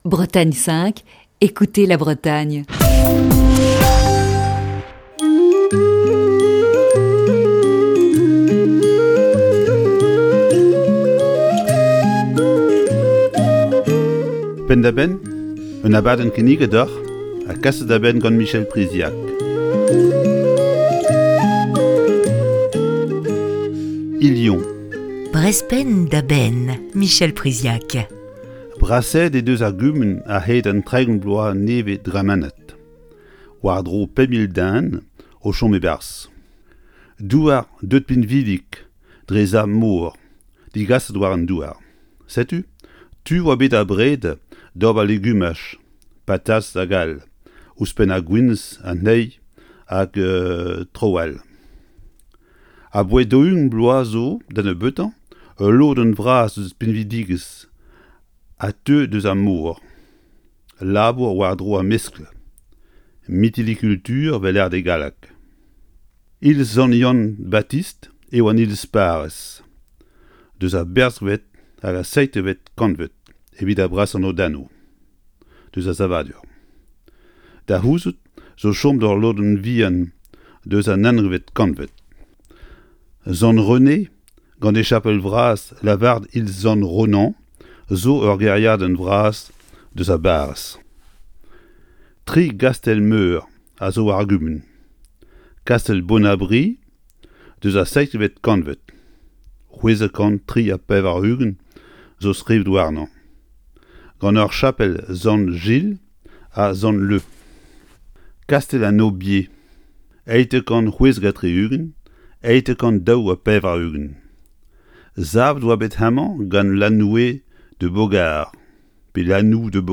Chronique du 26 août 2020.
(Chronique diffusée le 12 février 2020).